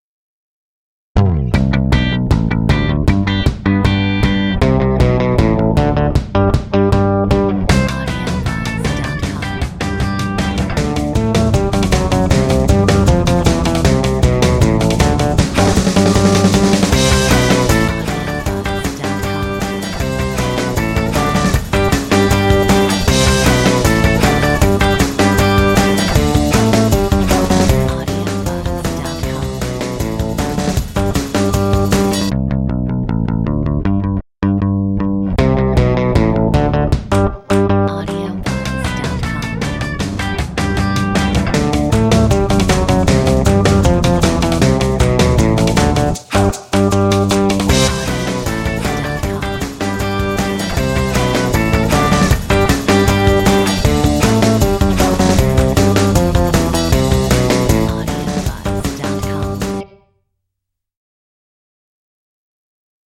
Metronome 160